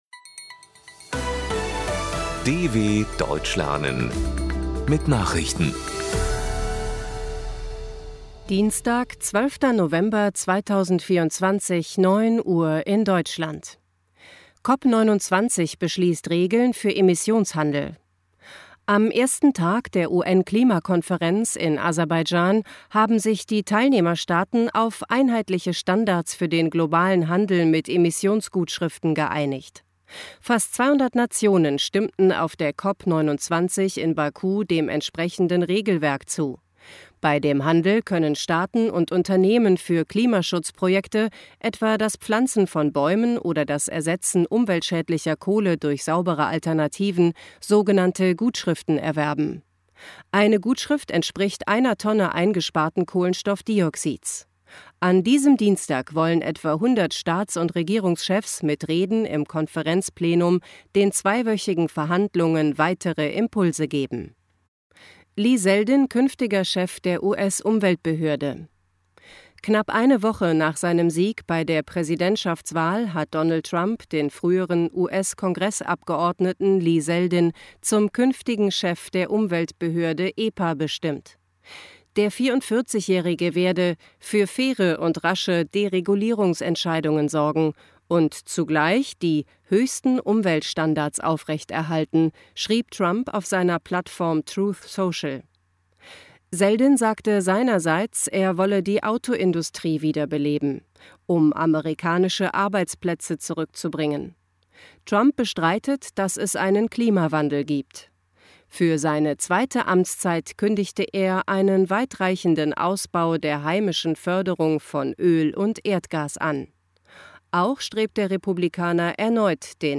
12.11.2024 – Langsam Gesprochene Nachrichten
Trainiere dein Hörverstehen mit den Nachrichten der Deutschen Welle von Dienstag – als Text und als verständlich gesprochene Audio-Datei.